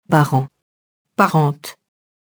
parent, parente [parɑ̃, -ɑ̃t]